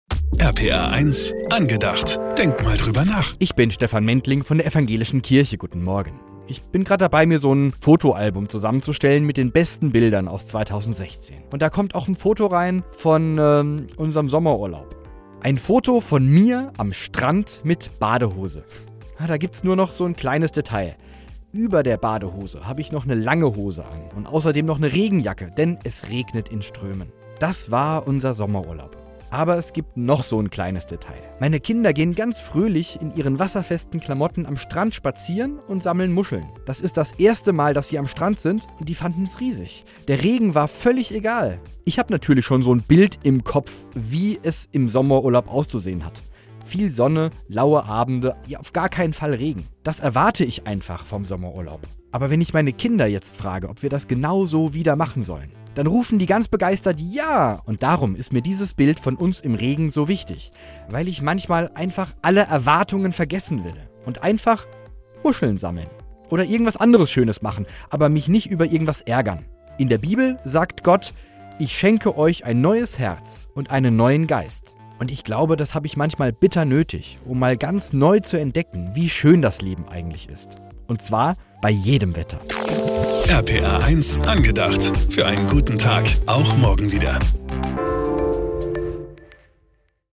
ProtCast Pfalz - Radioandachten aus Rheinland-Pfalz